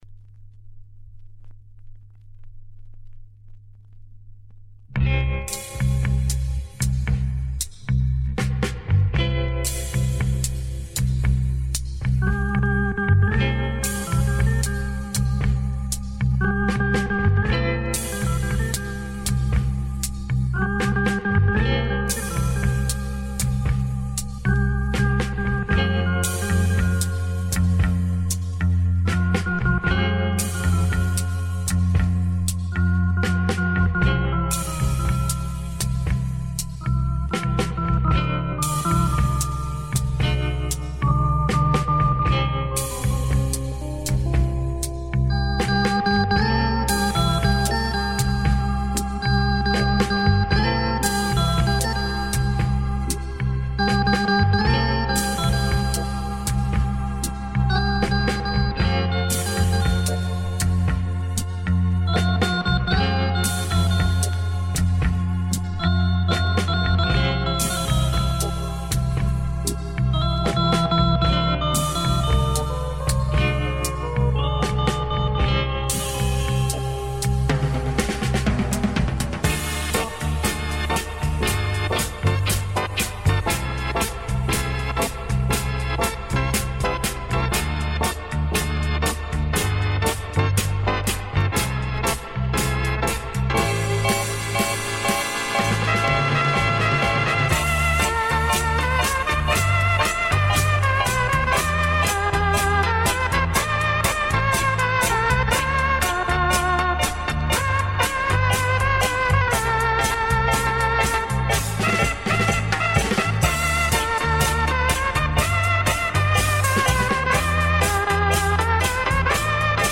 Released:Easy Listening